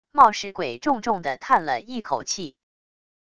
冒失鬼重重的叹了一口气wav音频